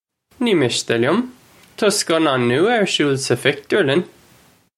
Nee mishtuh lyum. Taw skan-awn nyoo-a urr shool suh fictoorlun?
This is an approximate phonetic pronunciation of the phrase.